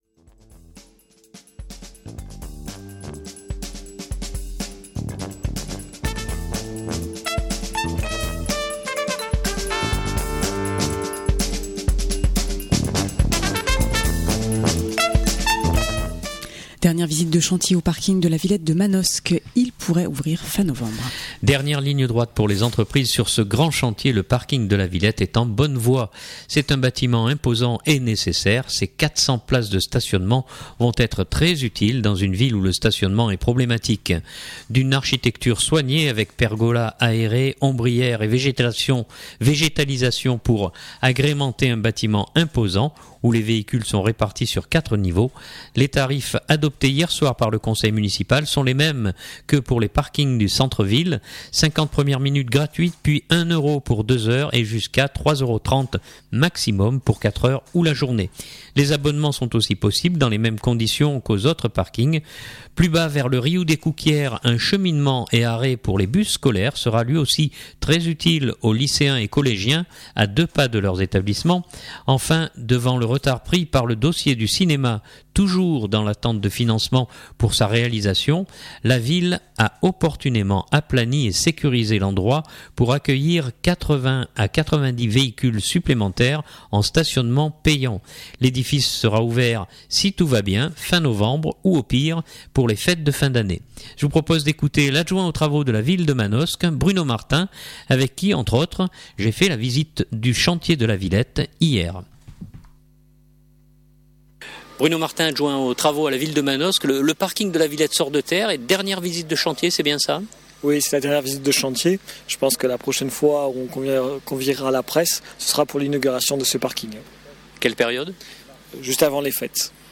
L’édifice sera ouvert si tout va bien fin novembre ou au pire pour les Fêtes de fin d’année. Je vous propose d’écouter l’adjoint aux travaux de la Ville de Manosque Bruno Martin avec qui, entre autres, j’ai fait la visite du chantier de La Villette hier.